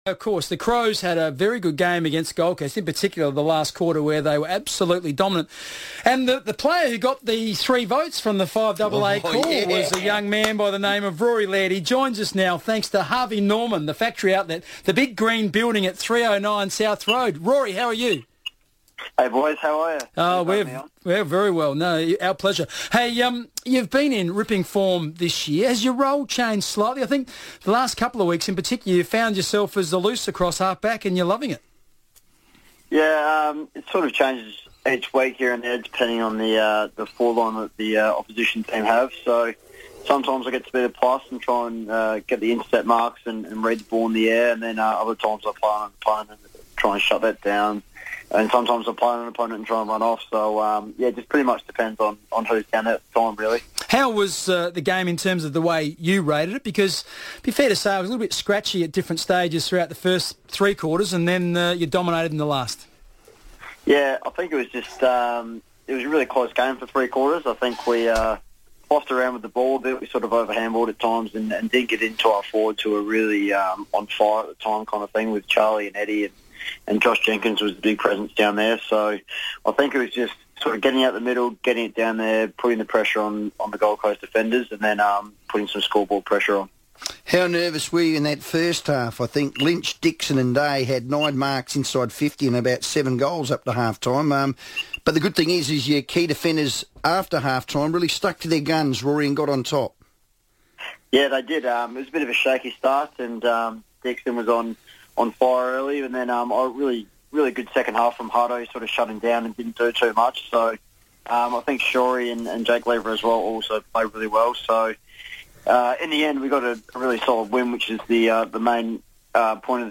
Defender Rory Laird joined the FIVEaa Sports Show ahead of his 50th AFL game against Sydney on Saturday.